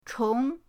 chong2.mp3